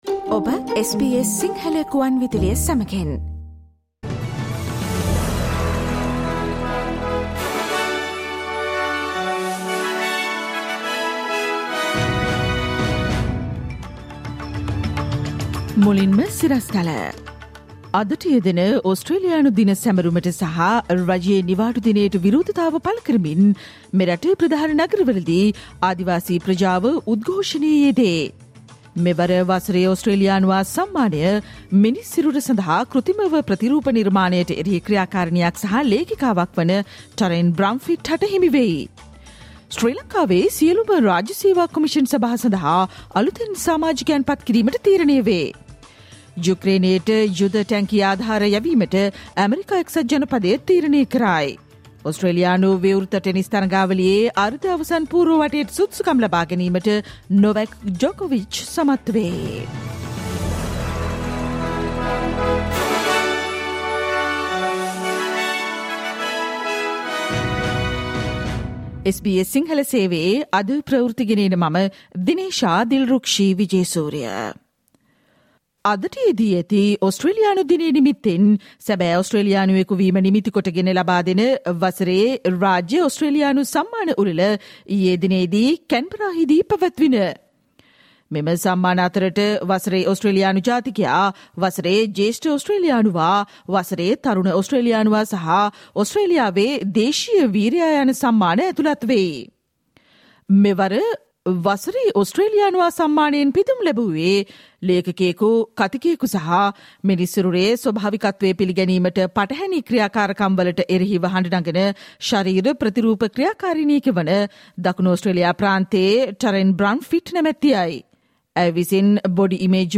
Listen to the SBS Sinhala Radio news bulletin on Thursday 26 January 2022